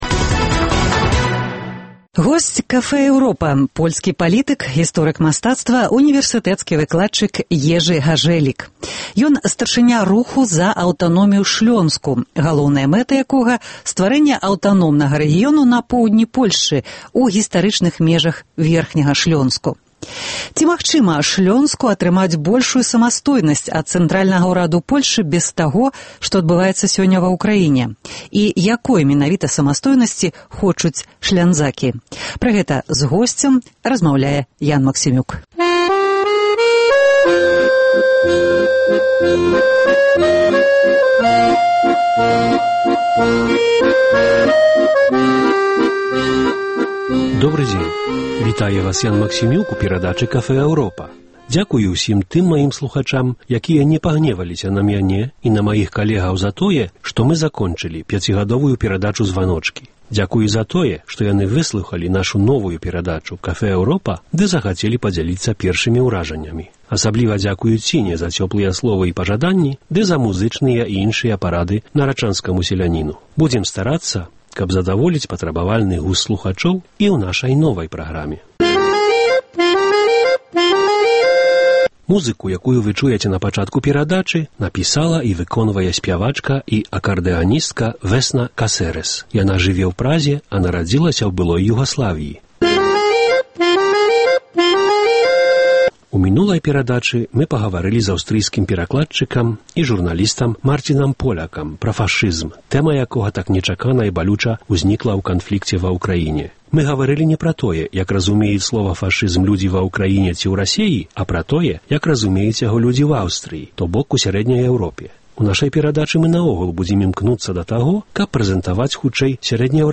Госьць Café Europa – польскі палітык, гісторык мастацтва, унівэрсытэцкі выкладчык Ежы Гажэлік.